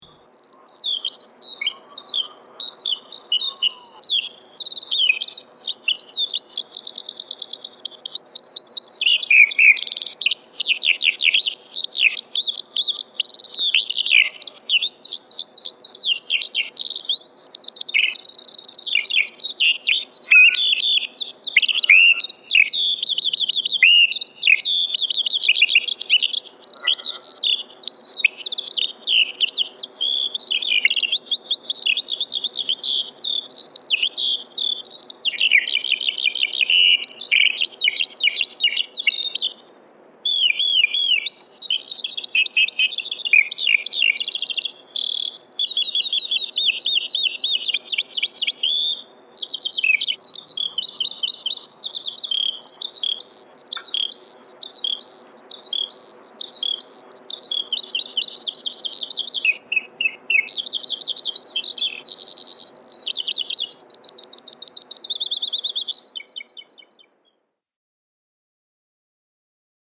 Skylark birdsong
A Lark or Skylark for those who didn’t know is a small bird, alas becoming rarer in the UK that soars high into the sky singing its delicate song.
Skylark-Late-Summer.mp3